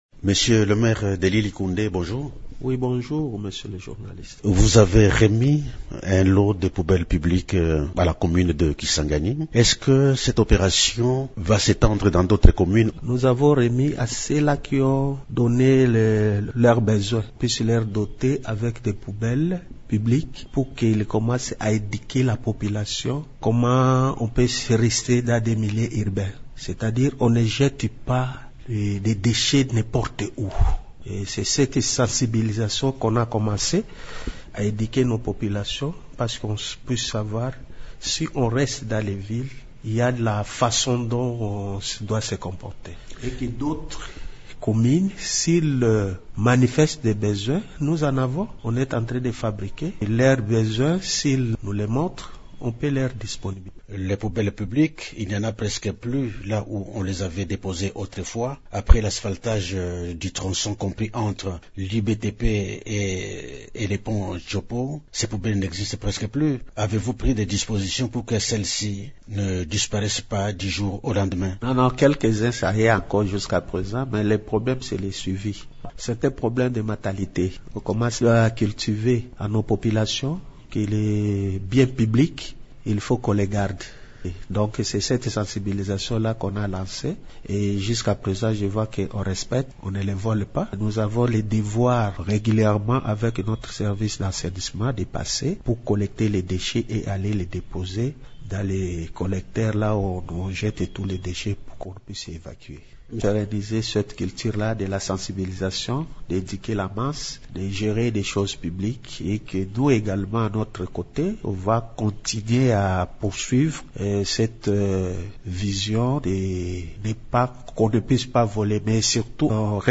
Invité de Radio Okapi ce vendredi 15 aout, l’autorité urbaine a détaillé les actions concrètes mises en œuvre pour redonner à Kisangani son éclat.